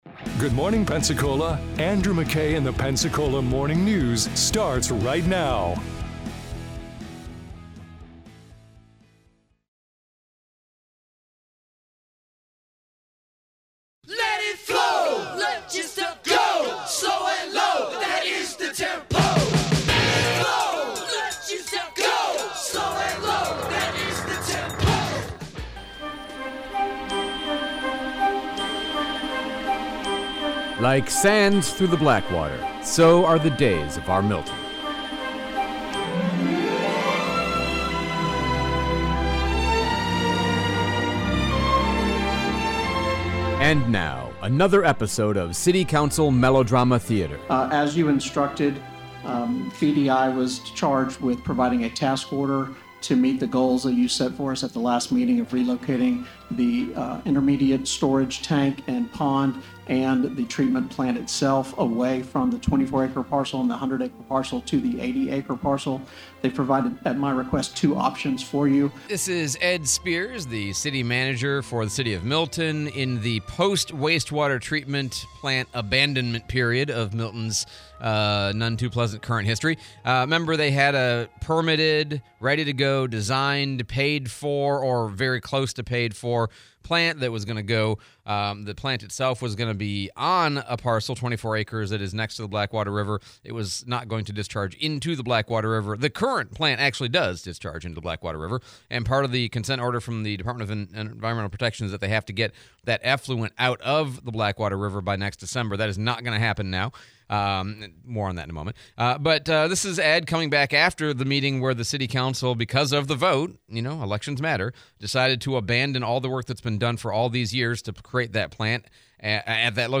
City of Milton Drama, Replay Mayor DC Reeves Town Hall